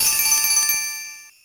［BGM・SE素材］
(上の2つの効果音を合成したもの)
sozai_harutoki3_se_suzu-b01.mp3